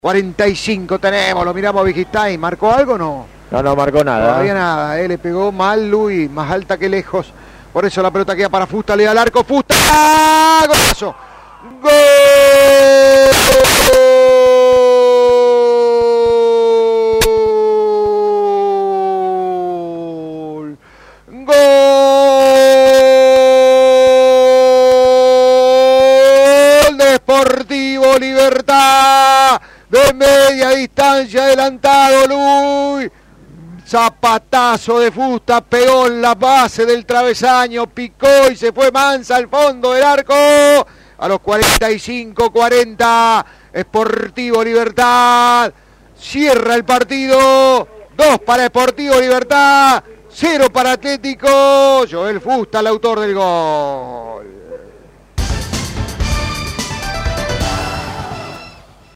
GOLES: